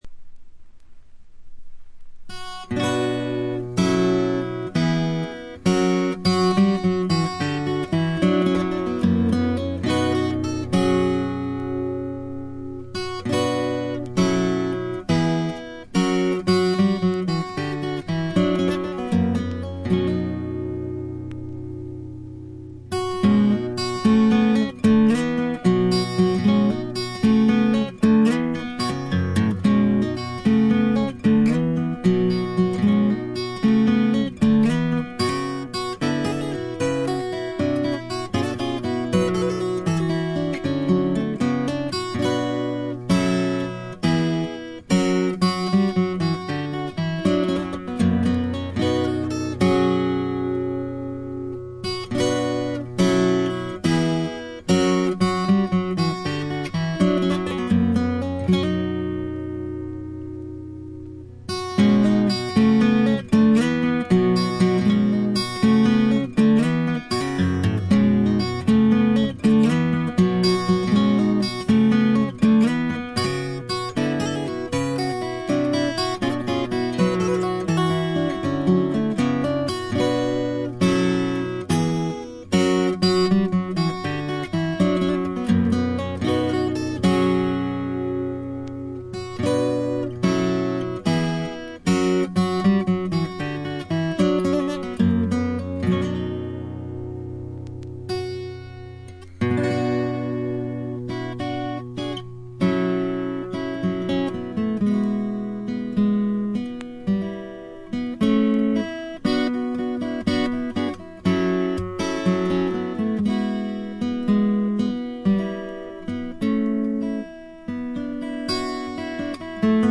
ごくわずかなノイズ感のみ。
両曲とも長尺で後半がヴォーカルパートになっています。
試聴曲は現品からの取り込み音源です。
Recorded at studio Kamboui, Chatellerault, France.